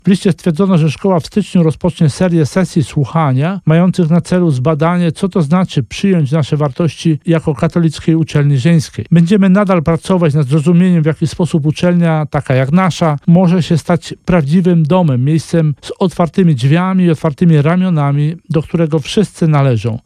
O zmianie decyzji władz uczelni mówi Jezuita